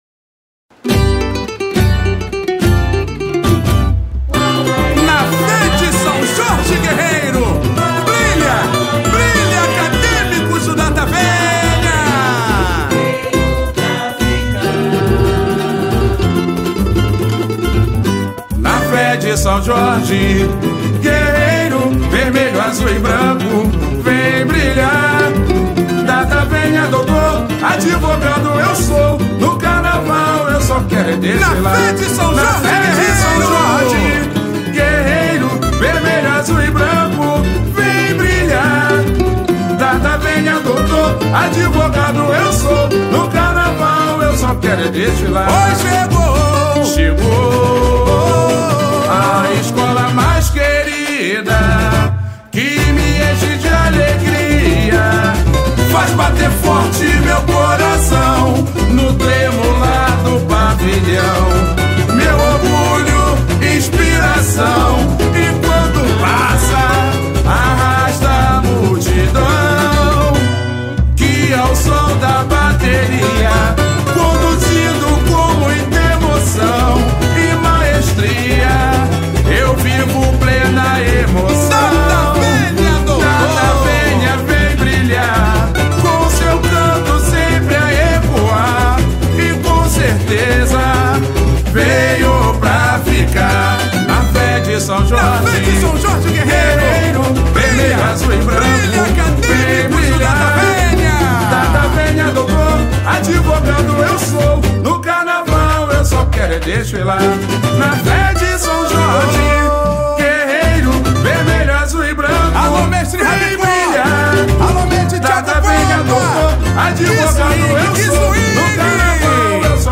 Samba-Exaltacao-Na-fe-de-Sao-Jorge.mp3